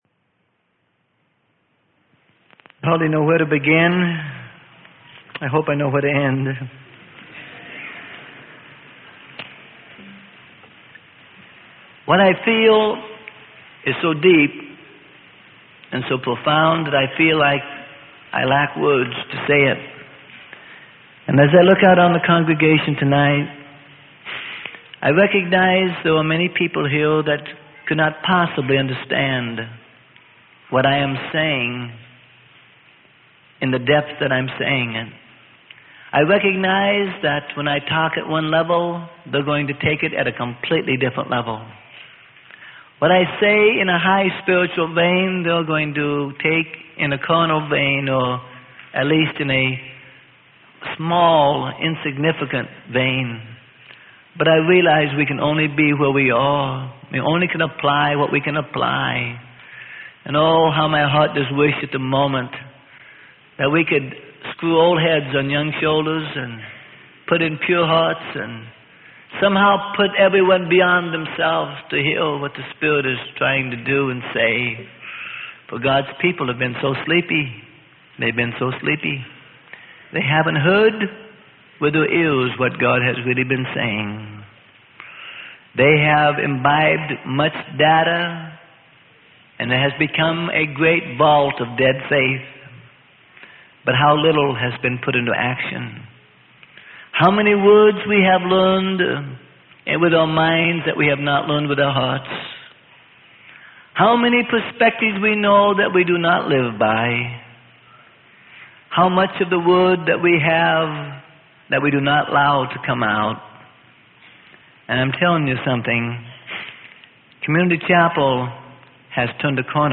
Sermon: God'S Blessing Or Judgment.